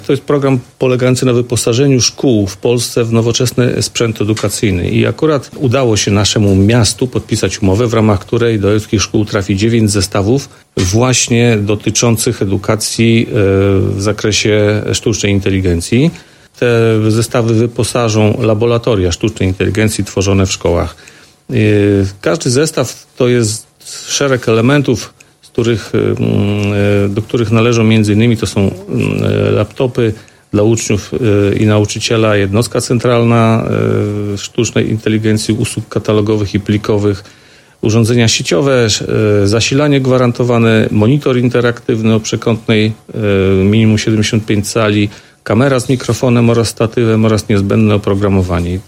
Mówił Mirosław Hołubowicz, zastępca prezydenta Ełku.